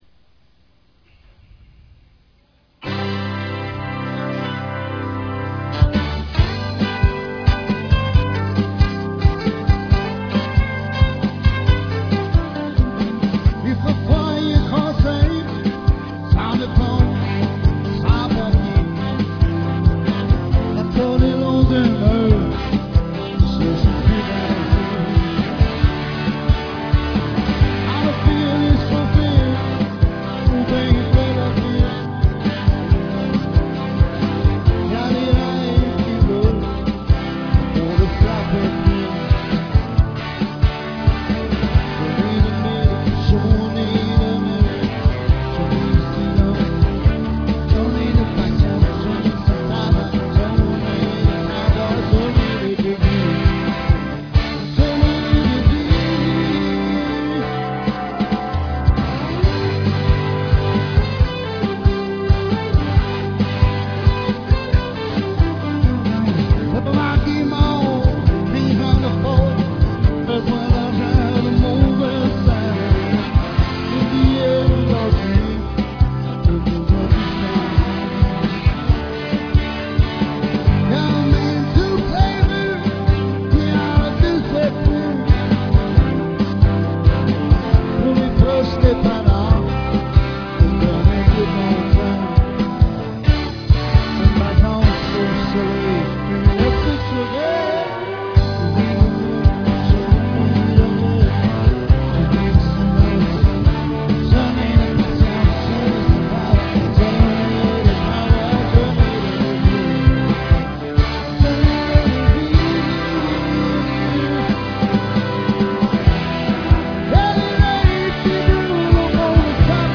VOICI UN PEU DE CHANSON QUE JE FAIS DANS LES BARS AU QUEBEC
Petite Vite avec le monde dans l'assistance